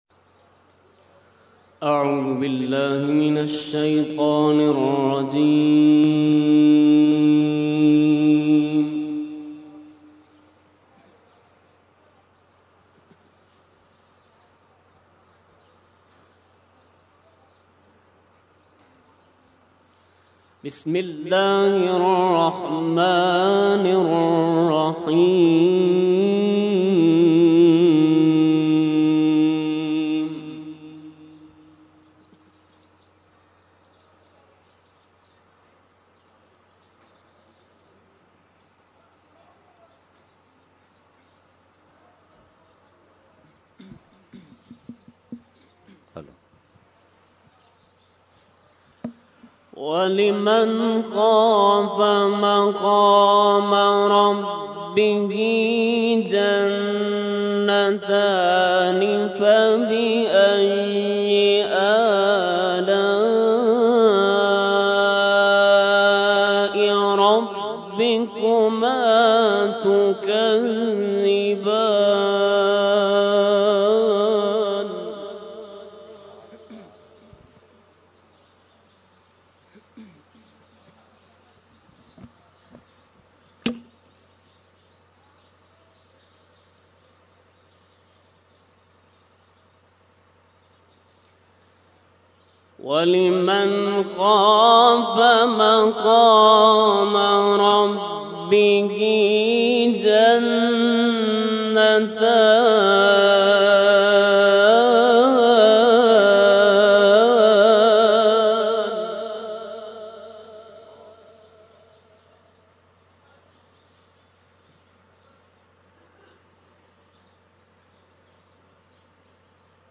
Category : Qirat | Language : ArabicEvent : Urs Makhdoome Samnani 2015